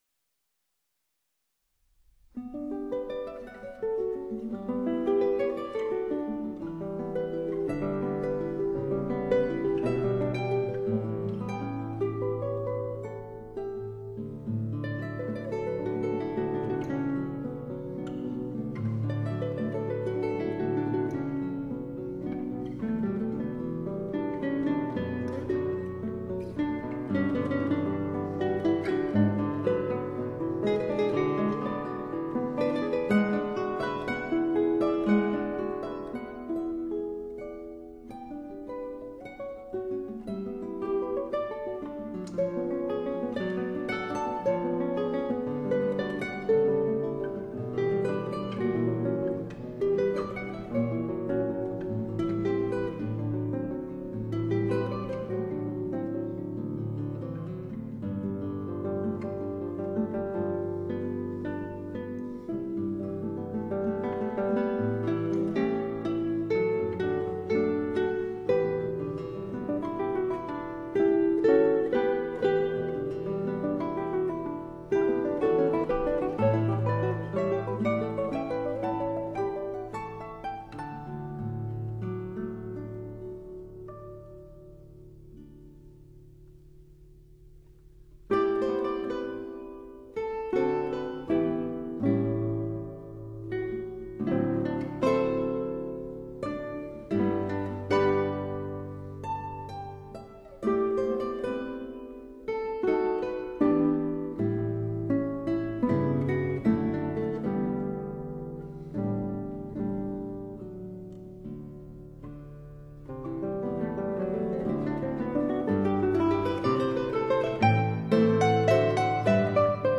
Arabesque No.1 in E major
Pro Arte Guitar Trio